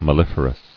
[mel·lif·er·ous]